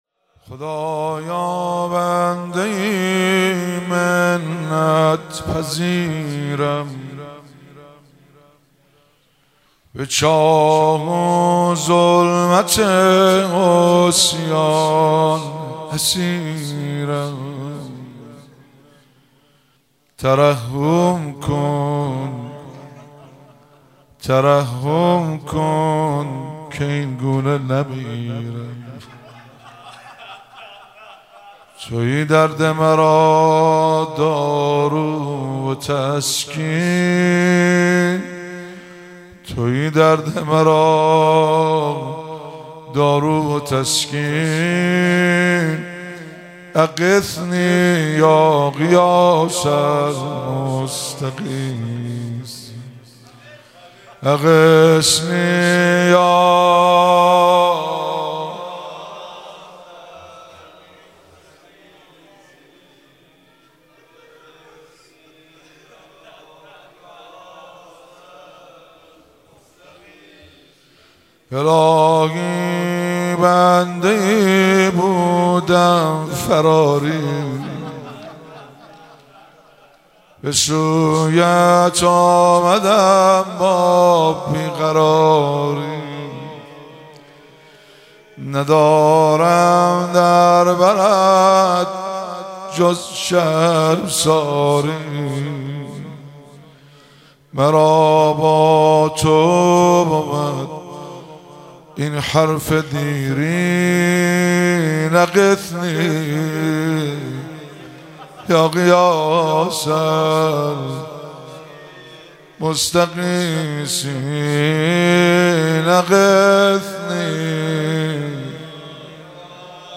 مراسم مناجات خوانی شب هجدهم ماه رمضان 1444
مناجات- خدايا بنده ای منت پذيرم